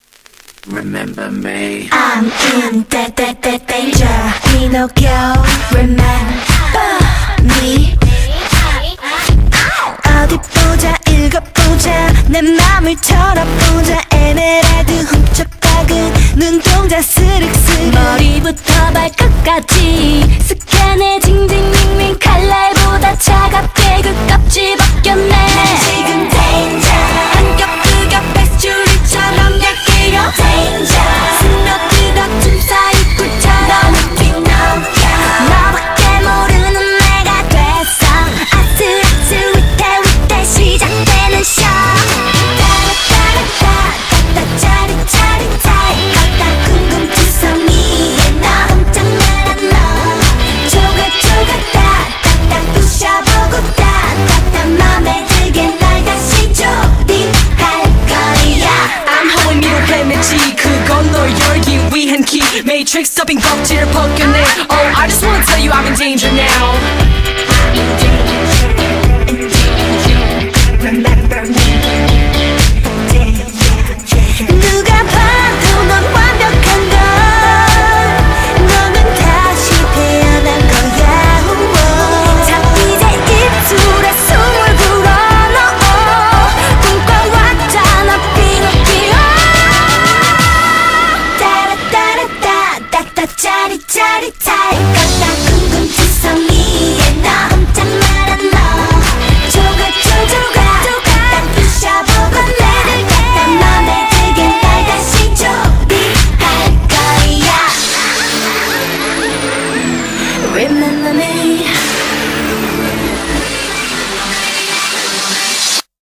BPM118
Audio QualityMusic Cut
K-Pop